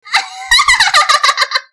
Evil Hahaha Sound Effect Download: Instant Soundboard Button